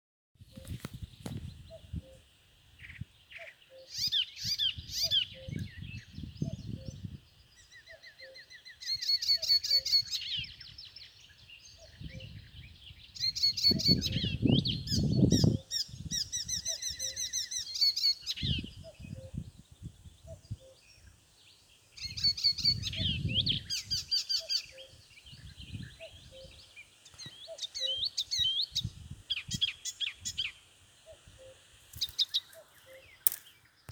Птицы -> Славковые ->
пересмешка, Hippolais icterina
Administratīvā teritorijaNīcas novads
СтатусПоёт